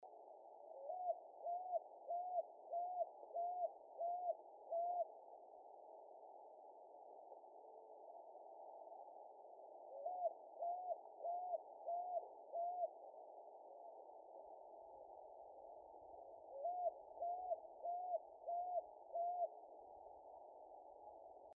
Picui Ground Dove (Columbina picui)
Life Stage: Adult
Province / Department: Jujuy
Condition: Wild
Certainty: Observed, Recorded vocal